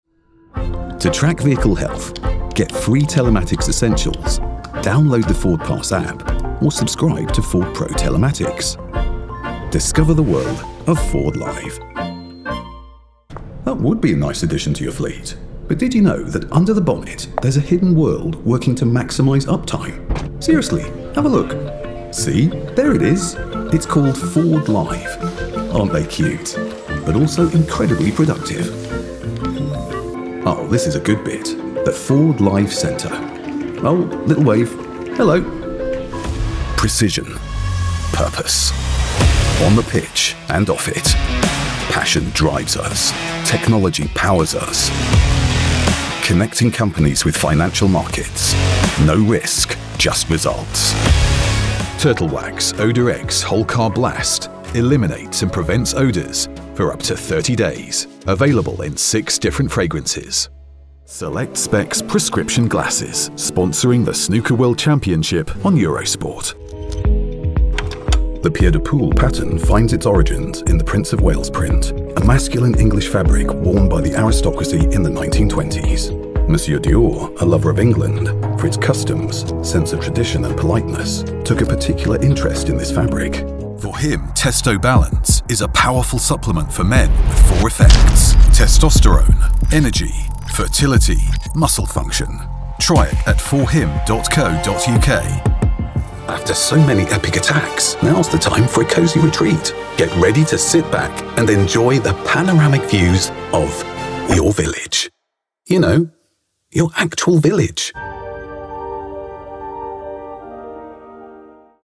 Englisch (Britisch)
Kommerziell, Tief, Natürlich, Unverwechselbar, Zuverlässig
Kommerziell